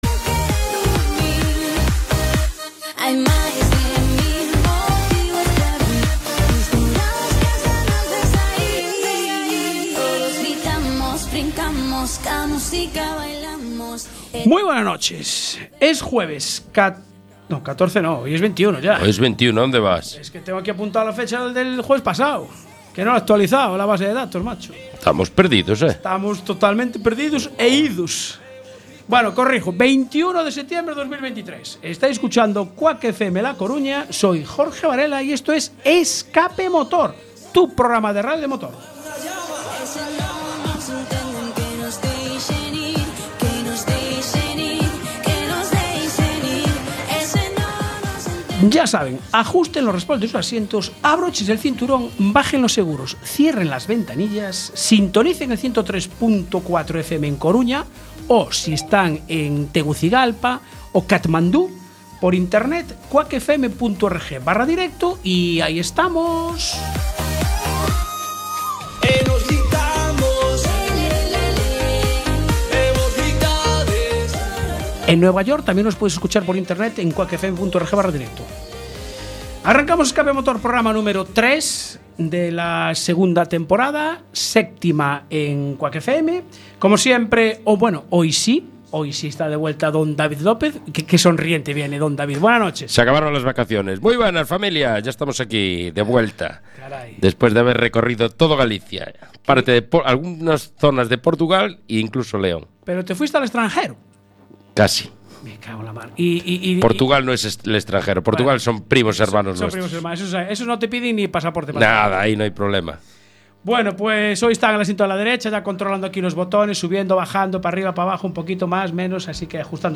Calendario de Eventos de Motor para el fin de semana. Nos visitó en el estudio